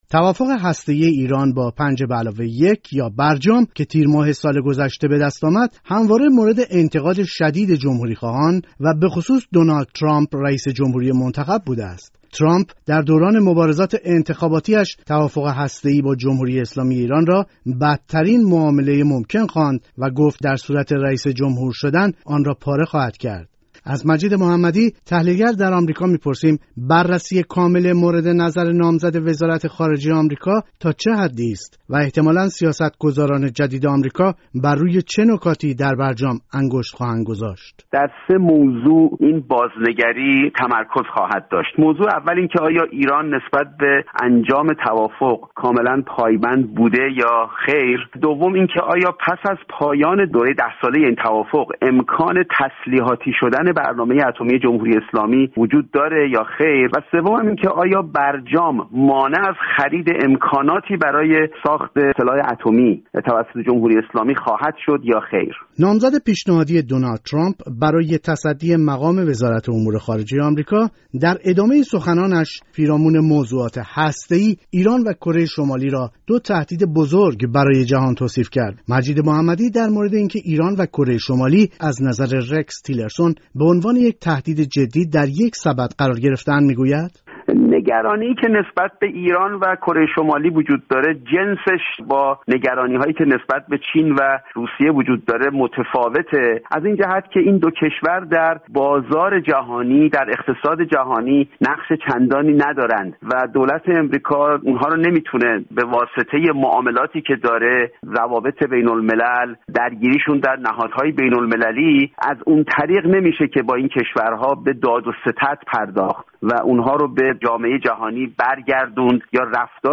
تحلیلگر سیاسی در آمریکا گفتگو کرده است.